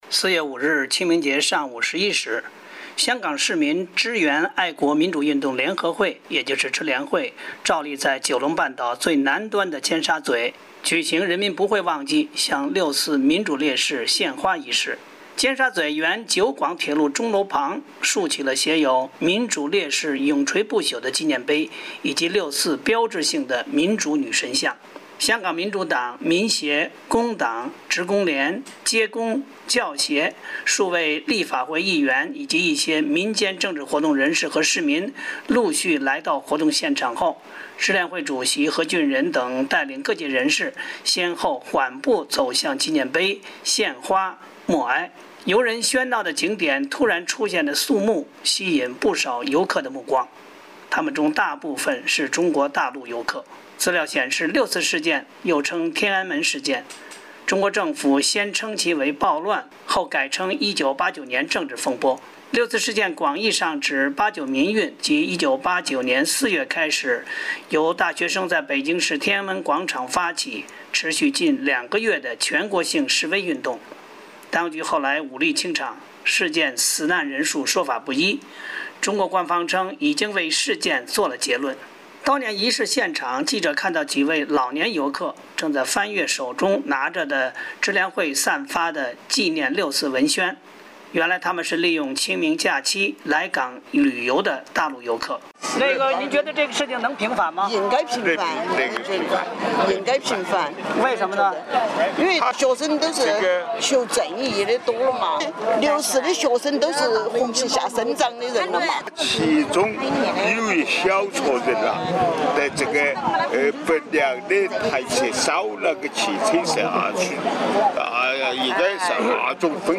游人喧闹的景点突然出现的肃穆气氛，吸引不少游客的目光。
美国之音记者采访他们时，他们表示应该平反六四。